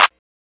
Common Clap 3 (CLAP).wav